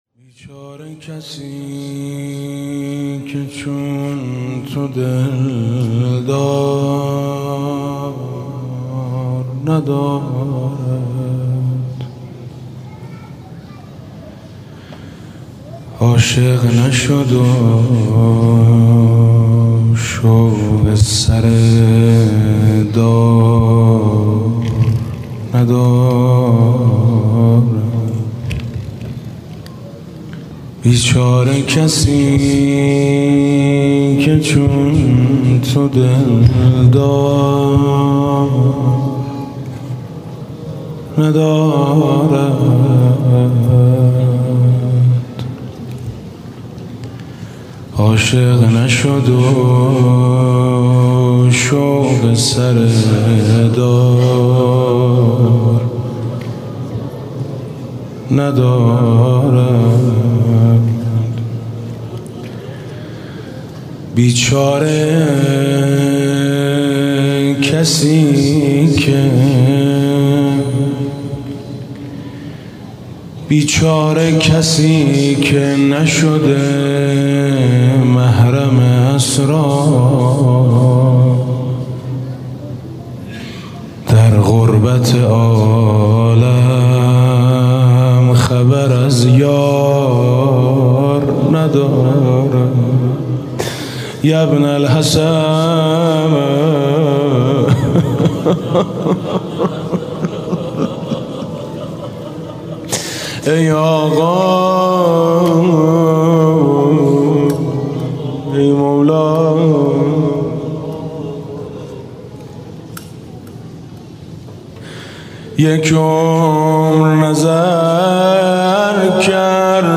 شب دوم استقبال از ماه رمضان 96 - مسجد بهشتی - نجوا با امام زمان (عج)